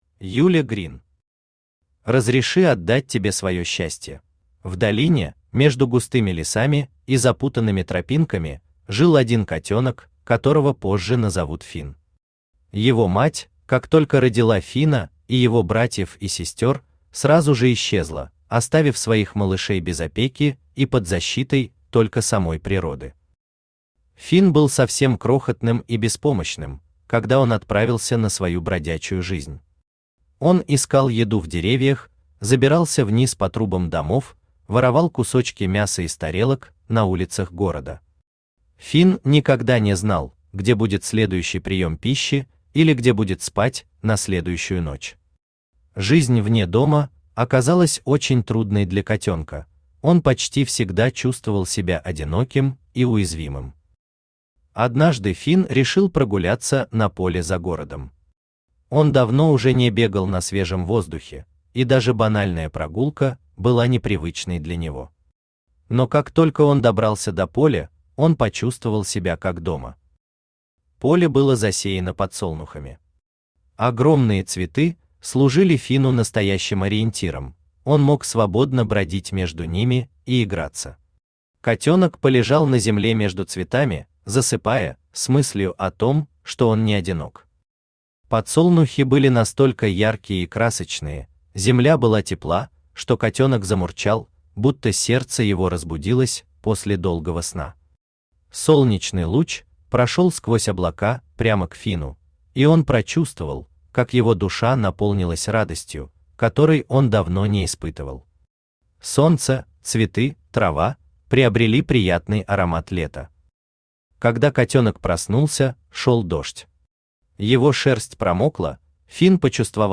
Аудиокнига Разреши отдать тебе своё счастье | Библиотека аудиокниг
Aудиокнига Разреши отдать тебе своё счастье Автор Юля Грин Читает аудиокнигу Авточтец ЛитРес.